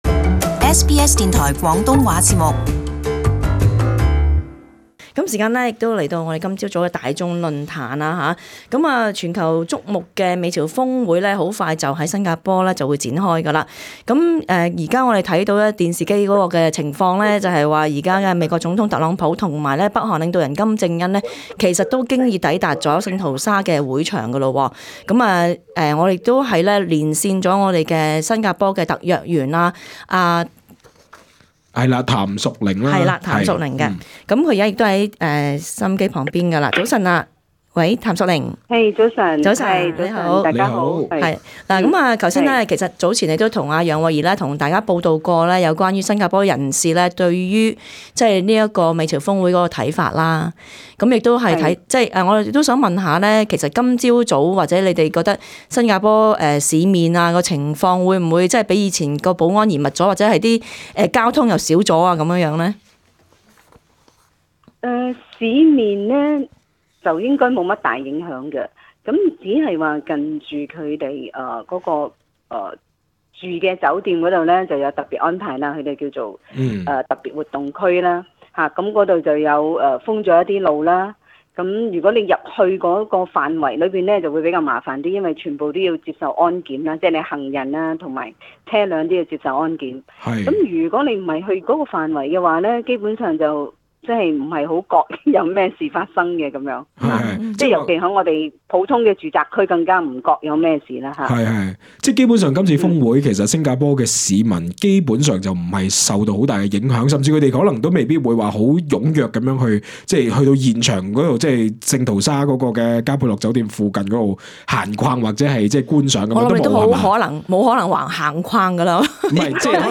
與聽眾談論：今次的美朝峰會,是否能促進朝鮮半島,全面廢除核武器。